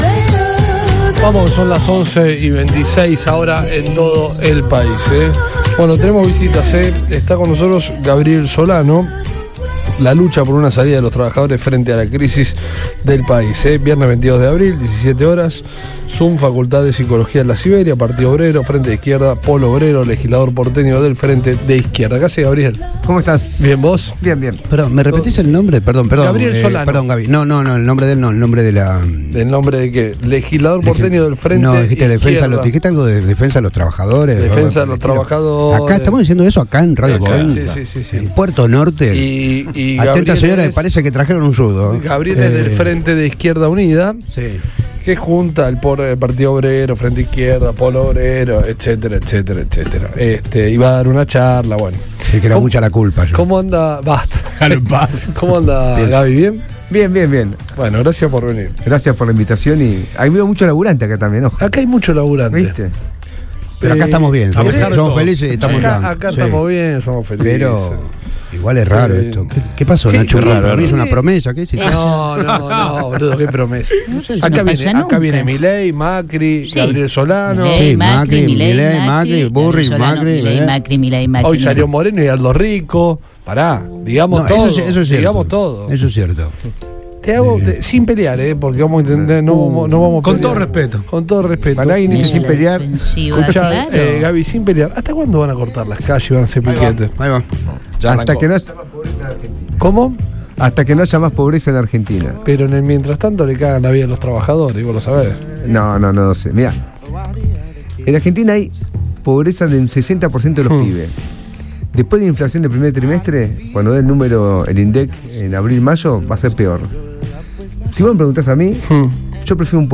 El legislador porteño del Frente de Izquierda Gabriel Solano visitó los estudios de Todo Pasa en Radio Boing